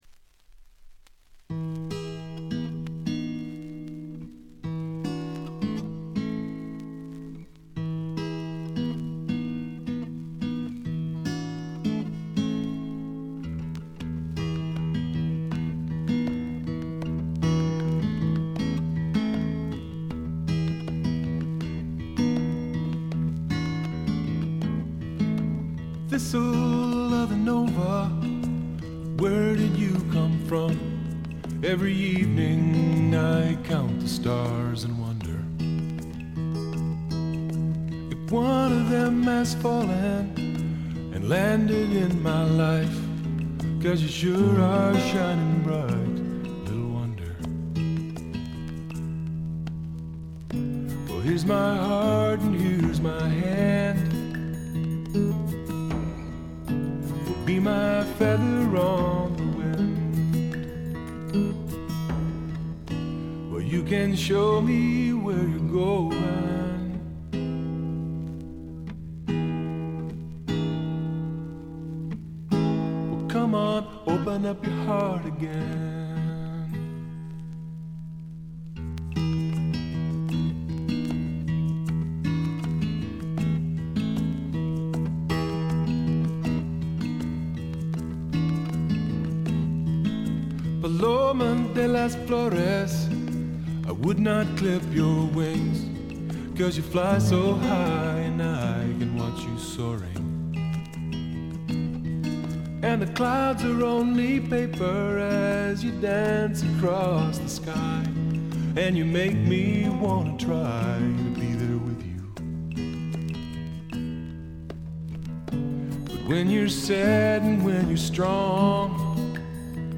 静音部で軽微なチリプチ。
メロウ系、AOR系シンガー・ソングライターのずばり名作！
試聴曲は現品からの取り込み音源です。